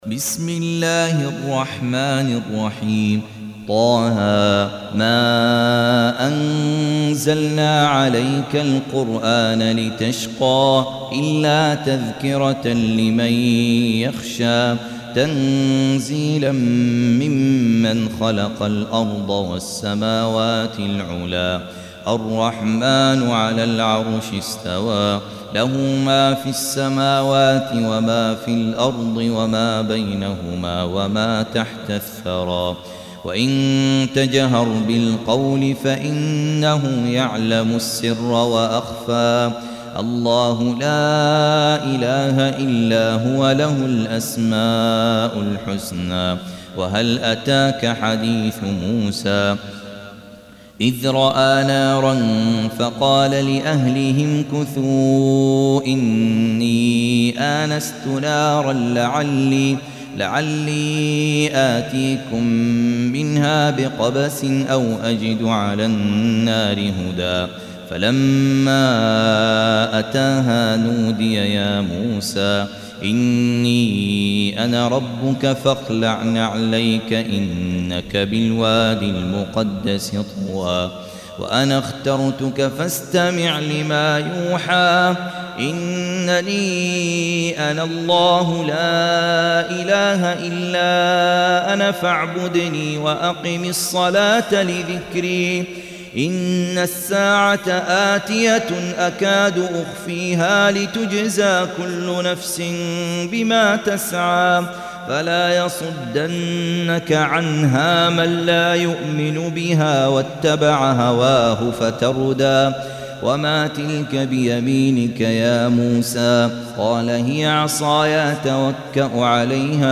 Surah Repeating تكرار السورة Download Surah حمّل السورة Reciting Murattalah Audio for 20. Surah T�H�. سورة طه N.B *Surah Includes Al-Basmalah Reciters Sequents تتابع التلاوات Reciters Repeats تكرار التلاوات